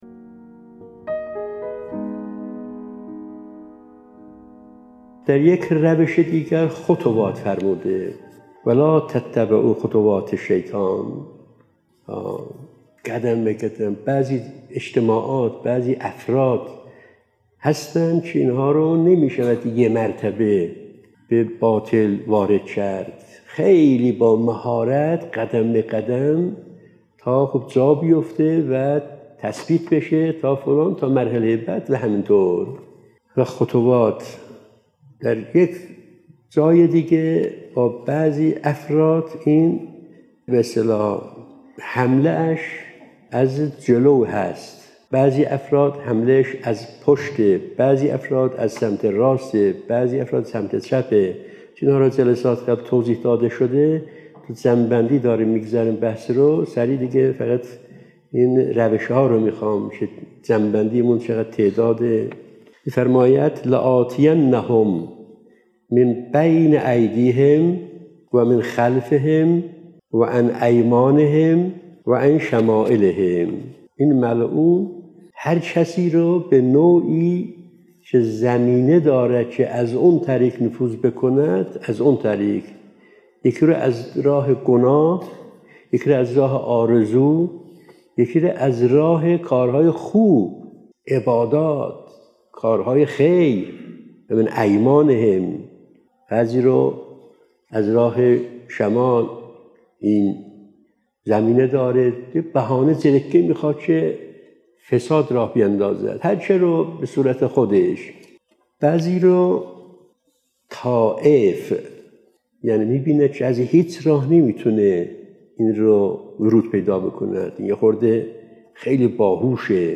📍از جلسه اولین جمعه ماه قمری| ماه ذی‌الحجه 🎙ابلیس| ترفندهای شیطان برای انسان در قرآن کریم(۳) 📌شماره(۱۹) ⏳۵ دقیقه 🔗پیوند دریافت👇 🌐